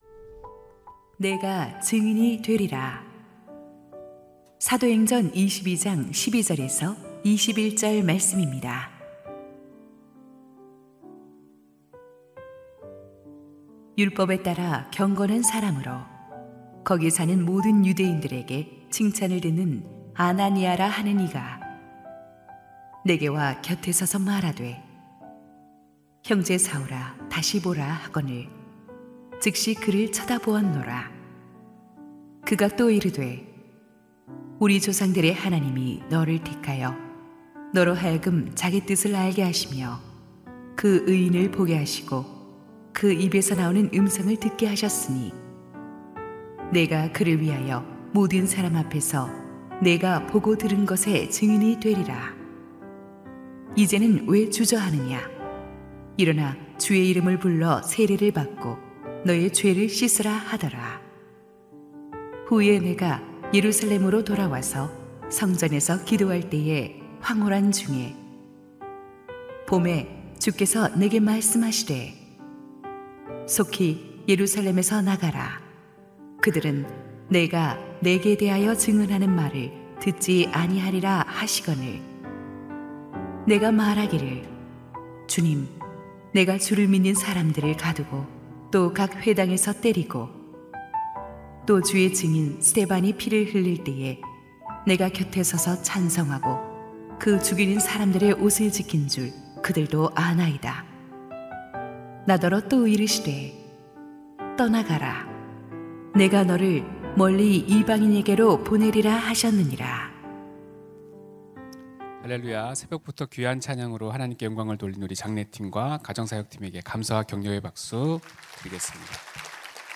2025-04-26 새벽기도회
[새벽예배] 2025-04-26 새벽기도회